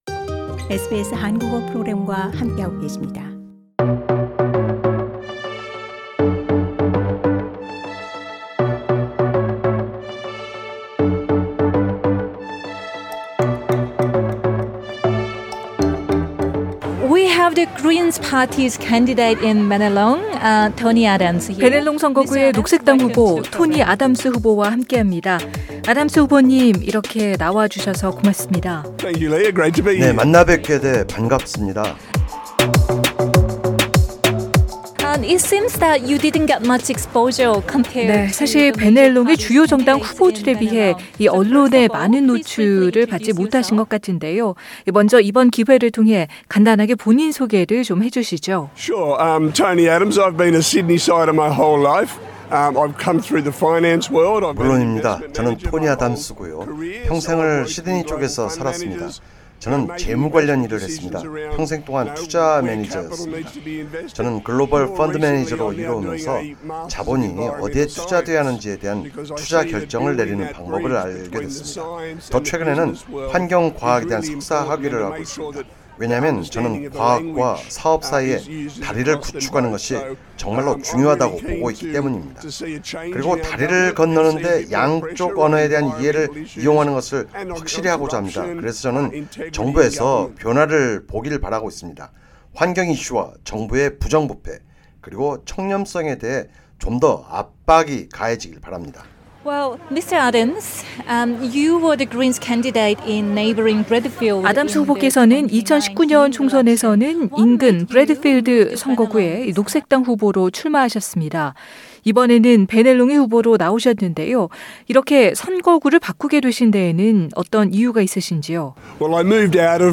2022 연방 총선 특집 연쇄 대담